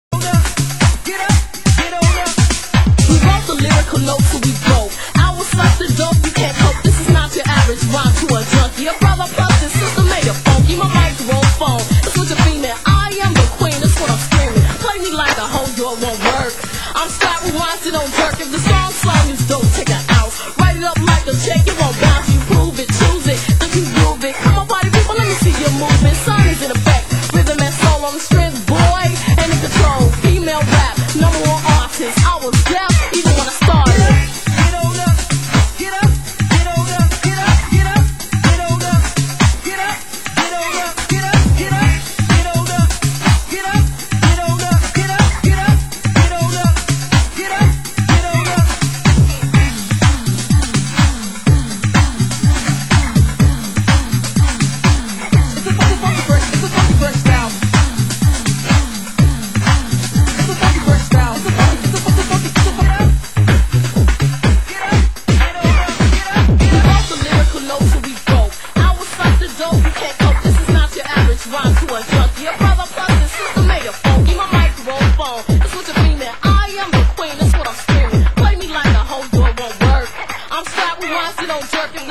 Genre: Warehouse